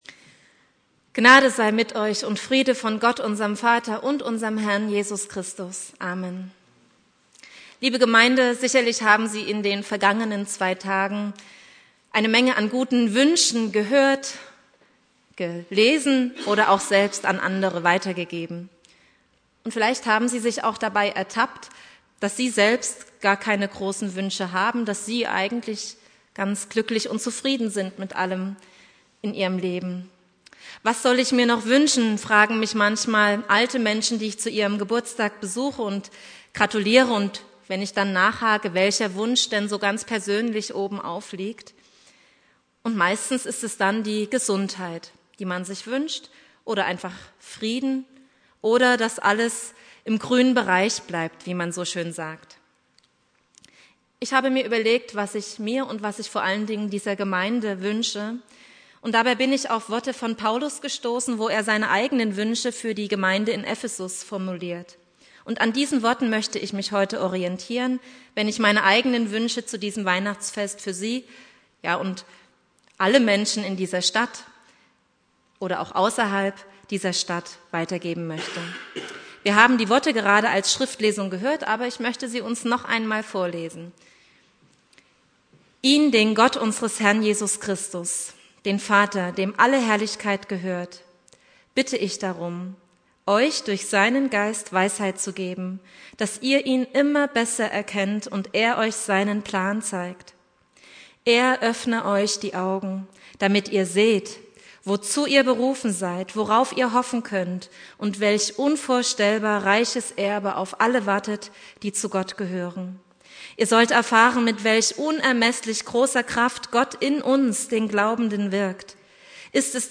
Predigt
2.Weihnachtstag Prediger